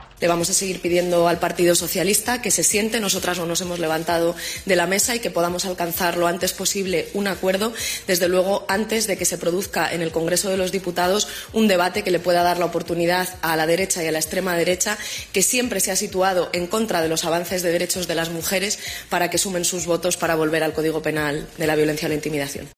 "Yo creo que esa respuesta tiene que ser una respuesta unitaria como Gobierno", ha subrayado durante unas declaraciones ante los medios este sábado antes del inicio del Encuentro Internacional Feminista 'We Call It Feminism', organizado por el Ministerio de Igualdad en la Facultad de Medicina de la Universidad Complutense de Madrid.